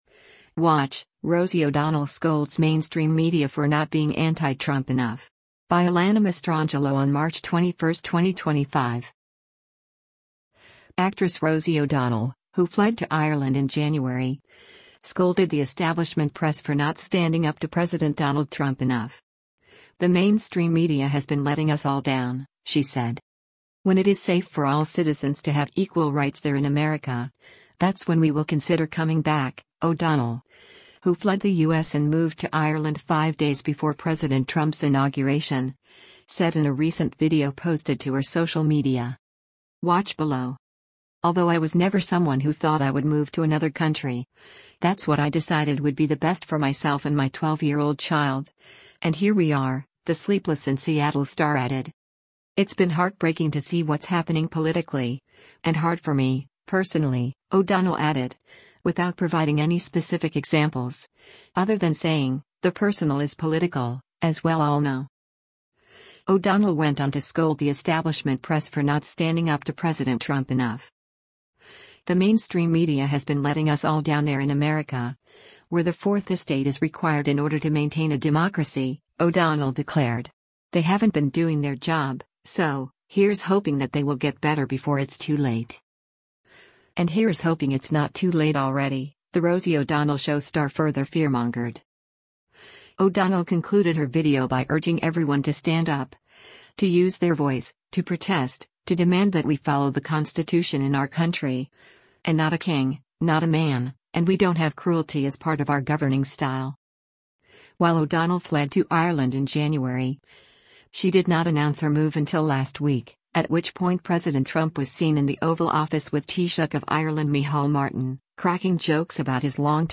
“When it is safe for all citizens to have equal rights there in America, that’s when we will consider coming back,” O’Donnell, who fled the U.S. and moved to Ireland five days before President Trump’s inauguration, said in a recent video posted to her social media.